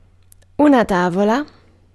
Ääntäminen
IPA : /plæŋk/